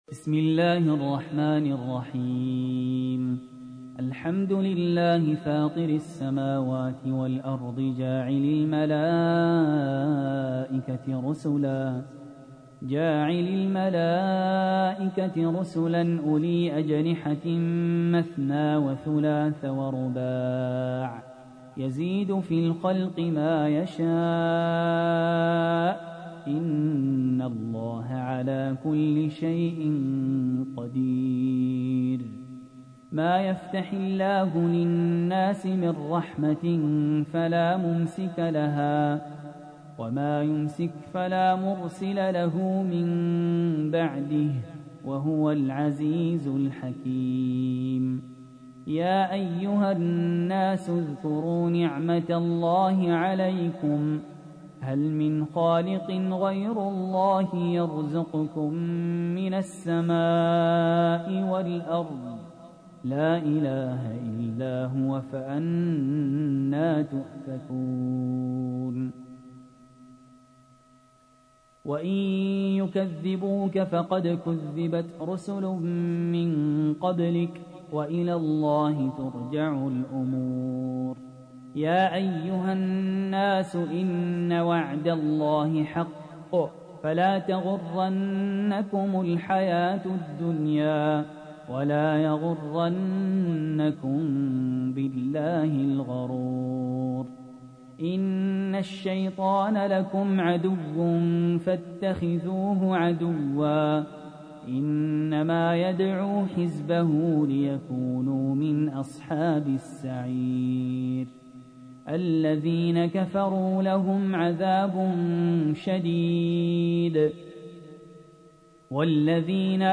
تحميل : 35. سورة فاطر / القارئ سهل ياسين / القرآن الكريم / موقع يا حسين